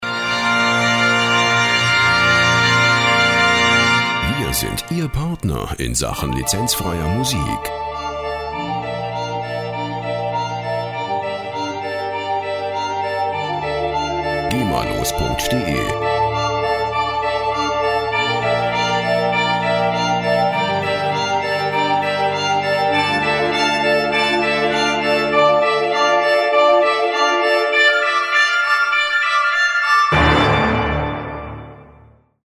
Der Klang der Musikinstrumente
Instrument: Kirchenorgel
Tempo: 100 bpm